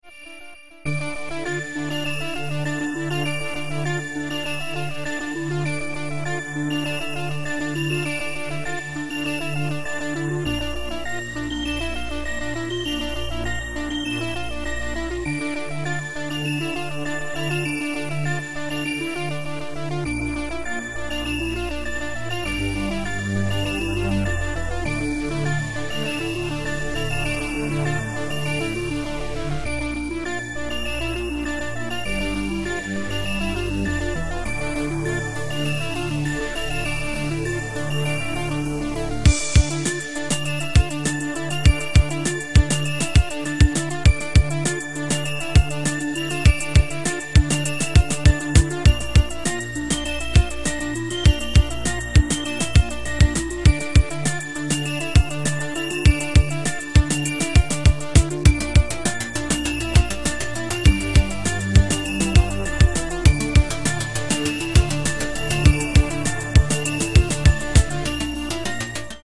• Качество: 128, Stereo
remix
без слов
электронные